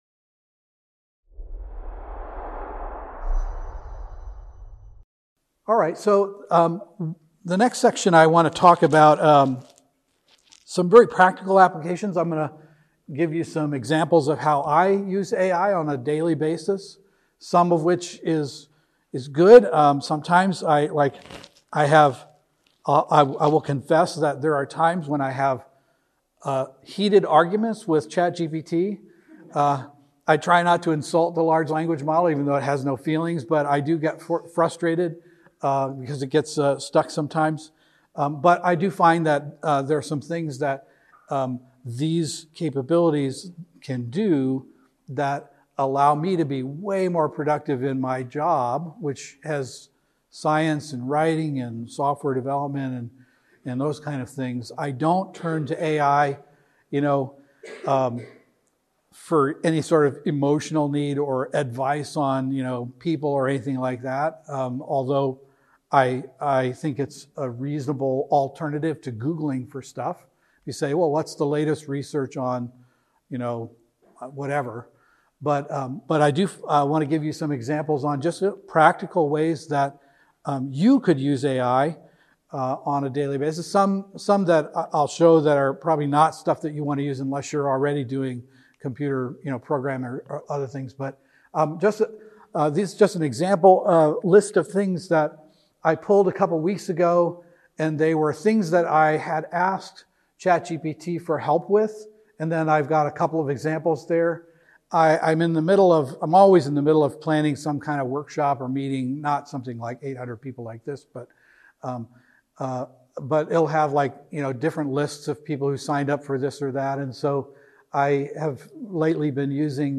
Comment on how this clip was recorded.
Event: ELF Pre-Forum Seminar